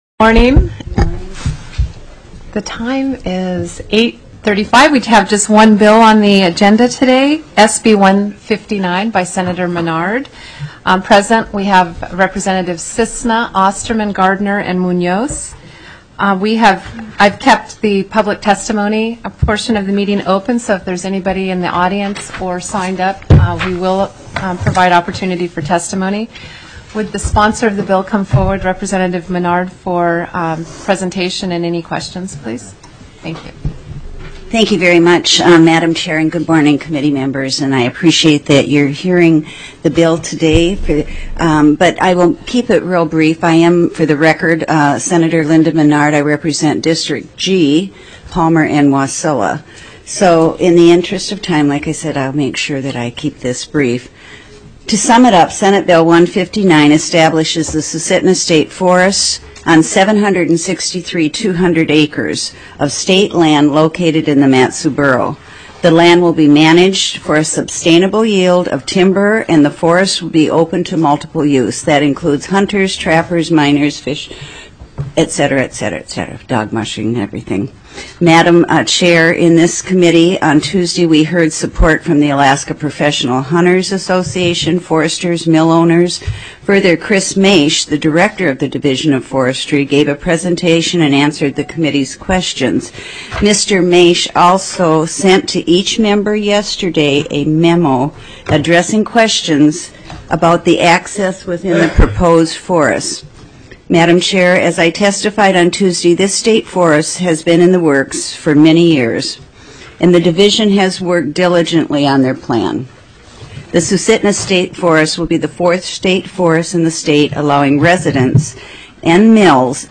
04/12/2012 08:30 AM House COMMUNITY & REGIONAL AFFAIRS